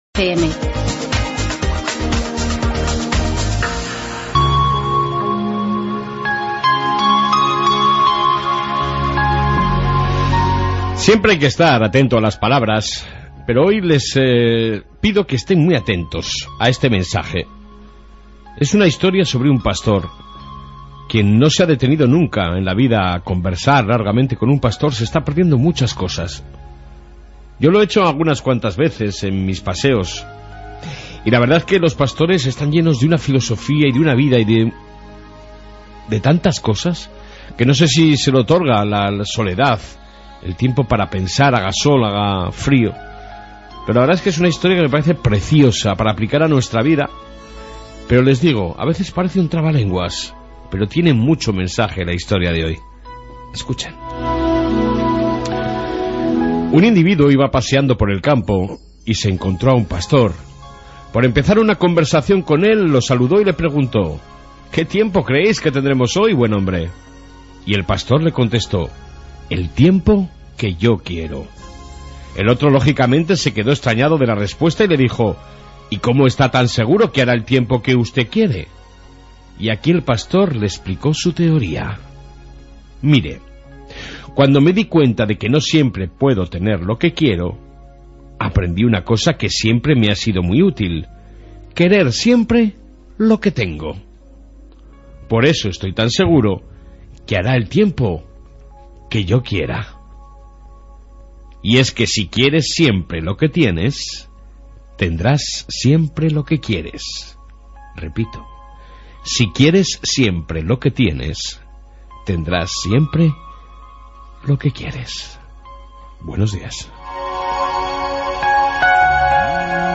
AUDIO: Reflexión diaria y Rueda de Prensa sobre deshaucios en la Ribera